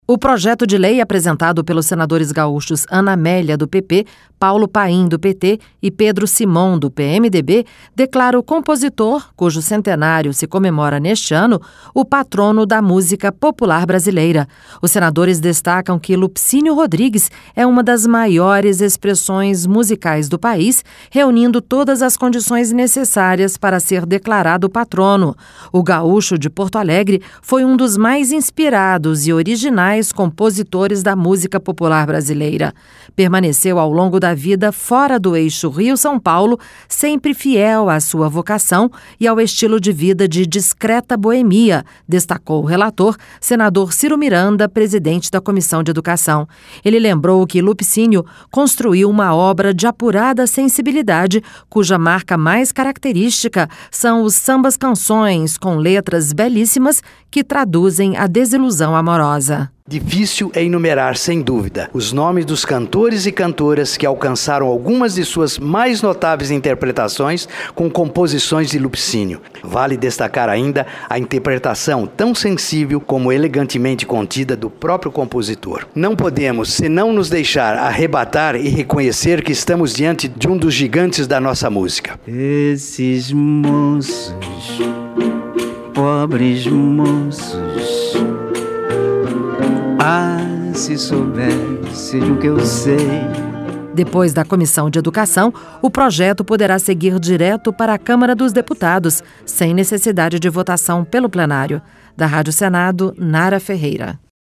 - musica sobe e desce.